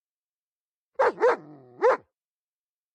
267灯泡音效03.mp3